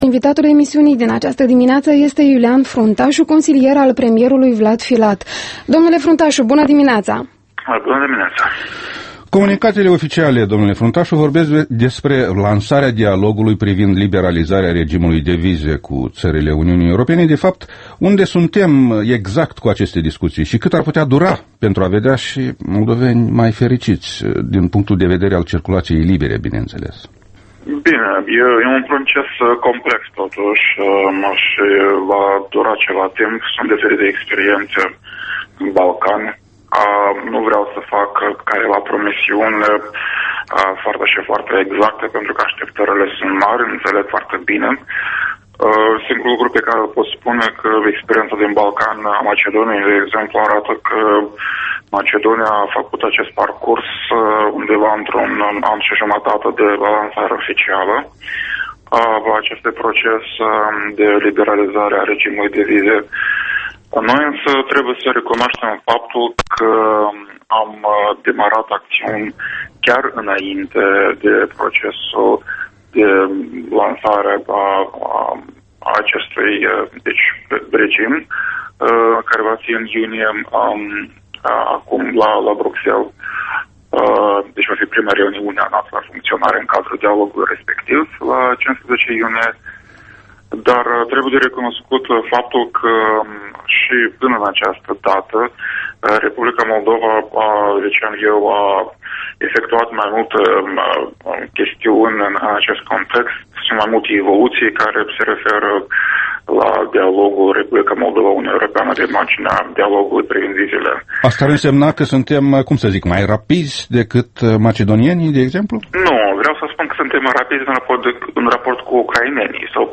Interviu matinal EL: cu Iulian Fruntașu